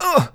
hurt6.wav